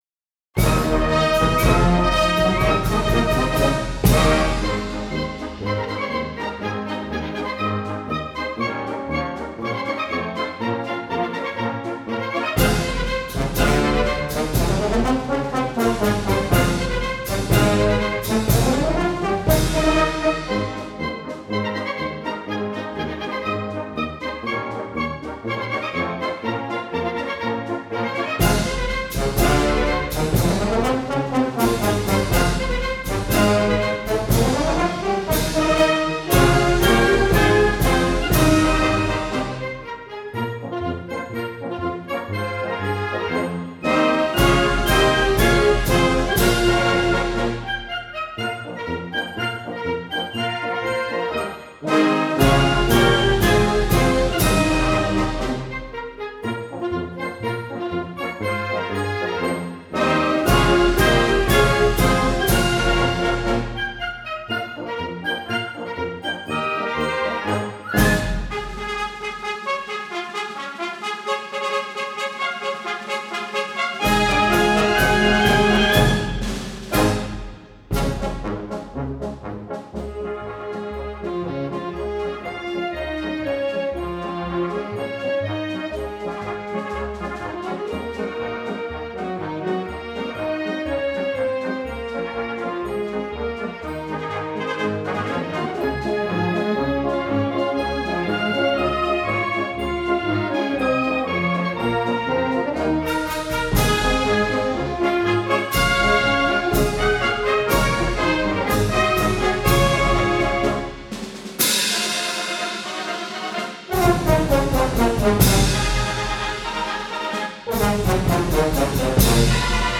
军乐